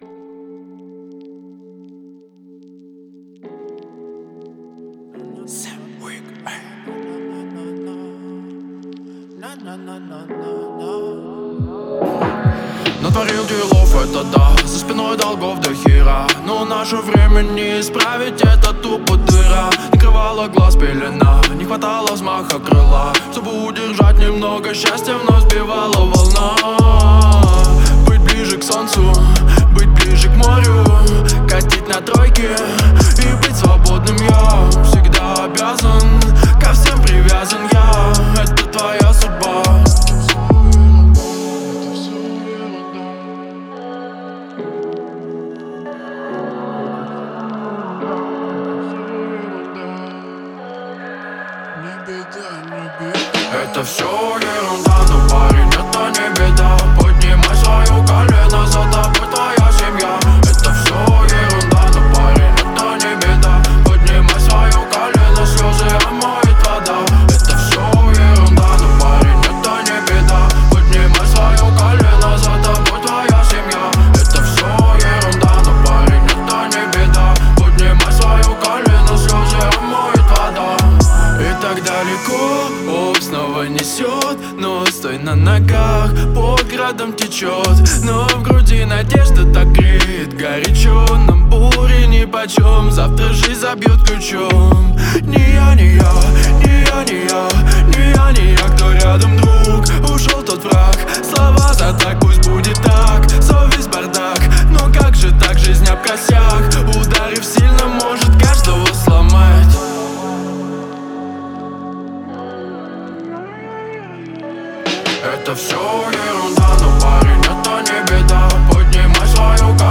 мелодичный вокал и запоминающийся припев